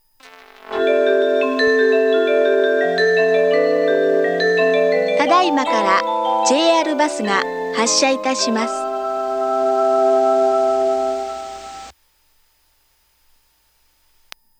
また、スピーカーはRAMSAです。
発車放送・メロディ
メロディの途中から割り込む形で放送が入ります。